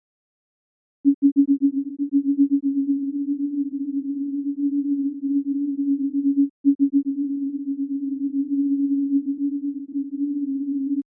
[002] これは、上の例ではpanningもConstant1のために定位が片寄っていたので、 panningをConstant2にして、設定範囲の0-4999の中央にしたものです。